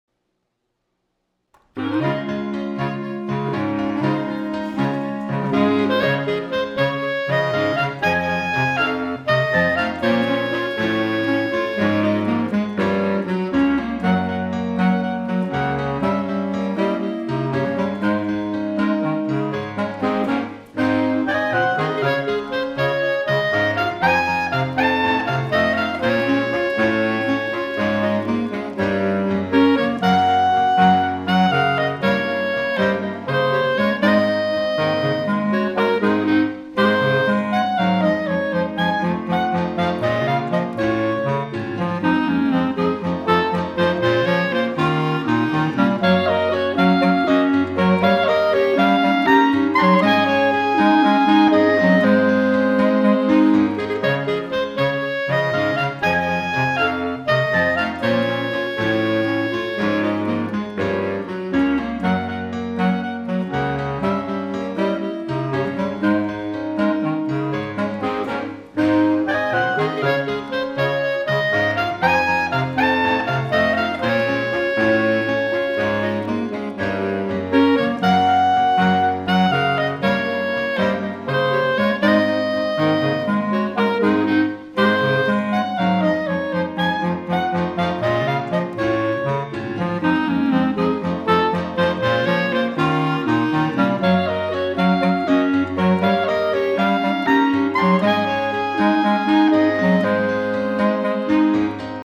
on Clarinet, Saxophone, Flute, and Piano.